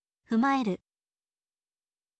fumaeru